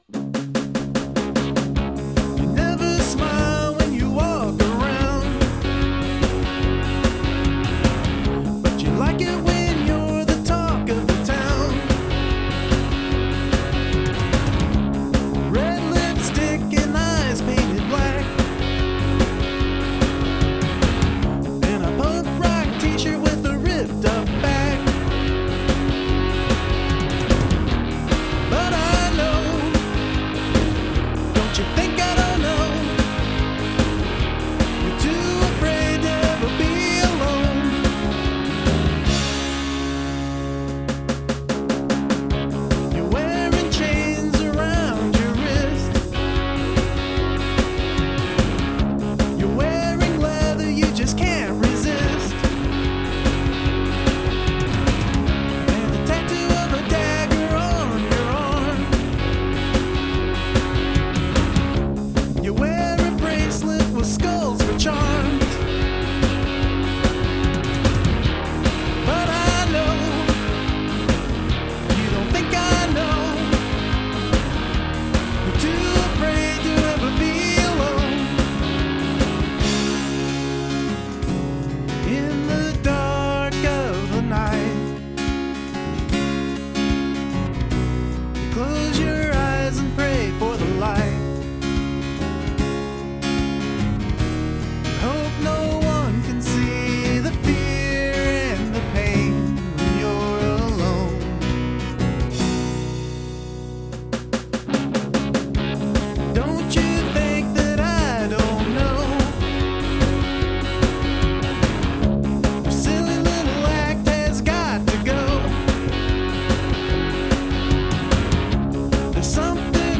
Trying to rock more than singer-songwrite.
I like the upbeat tempo, percussion, and electric mixed in.
A very catchy tune!!!
Good recording!!